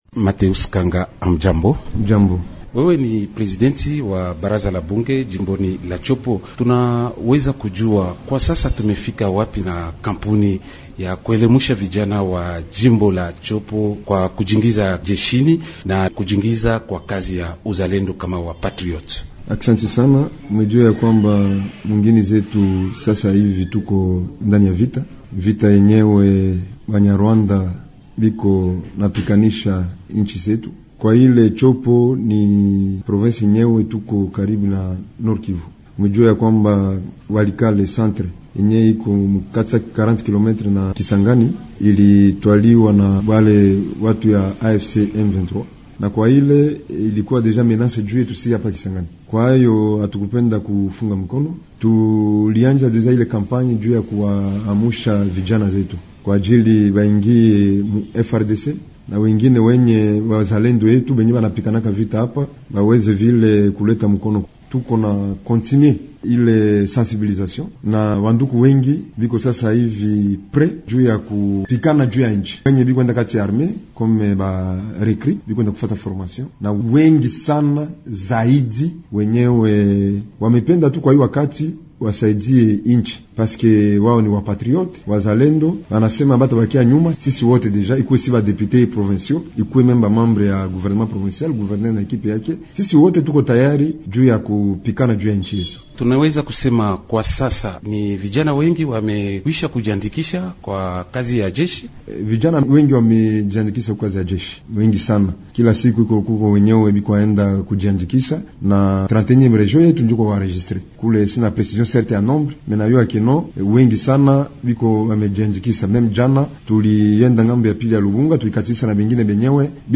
Mgeni wetu ni Mateus KANGA, mkuu wa bunge la mkoa wa Tshopo. Pamoja naye, tunazungumzia suala la uzalendo katika kipindi hiki cha vita katika Jamhuri ya Kidemokrasia ya Kongo.